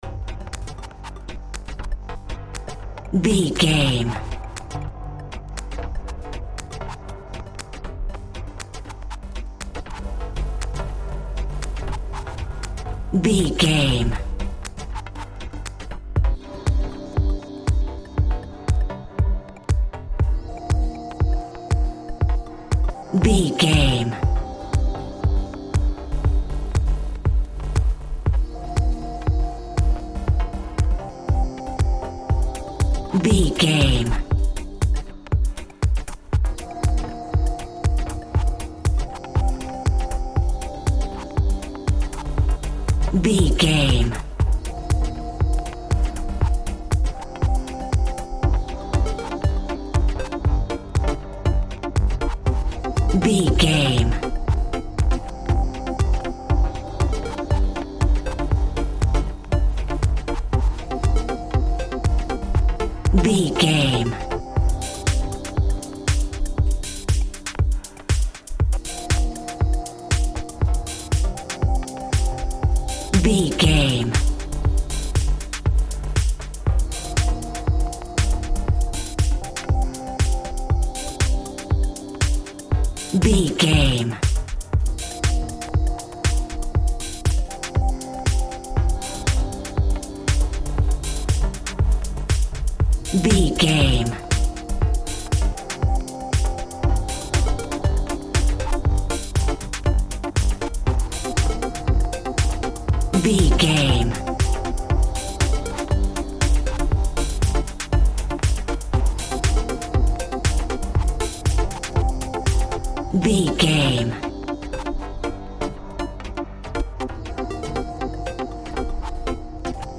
Aeolian/Minor
groovy
uplifting
futuristic
driving
energetic
repetitive
synthesiser
drum machine
house
electro dance
techno
trance
synth leads
synth bass
upbeat